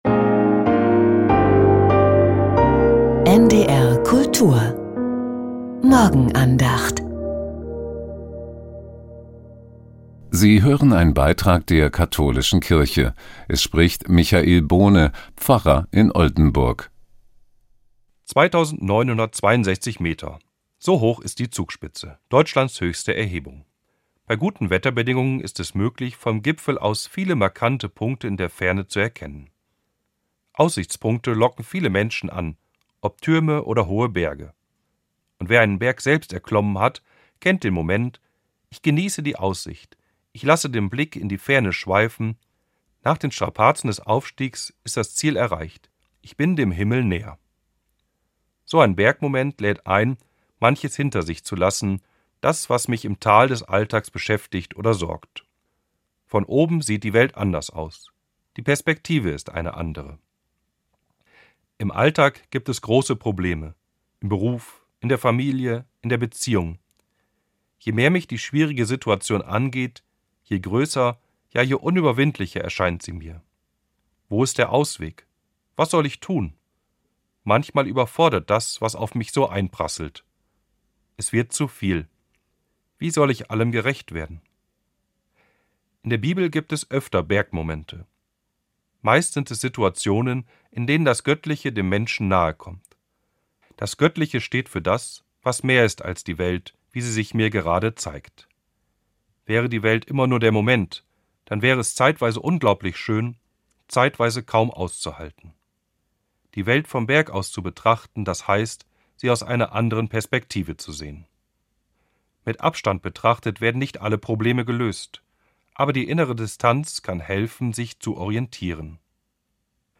Auf dem Gipfel ~ Die Morgenandacht bei NDR Kultur Podcast